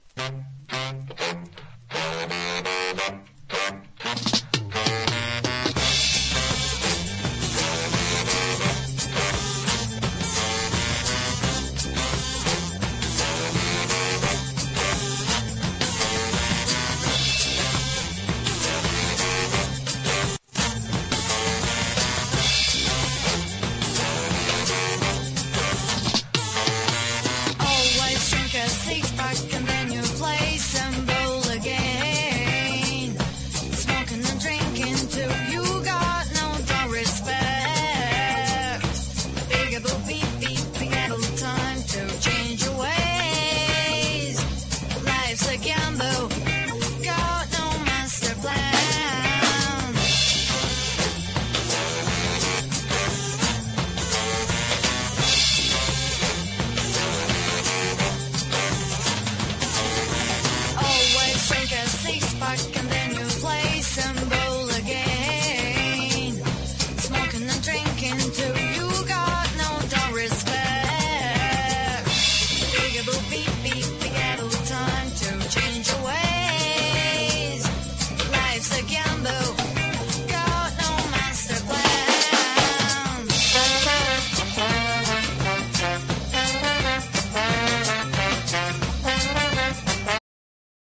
• HIPHOP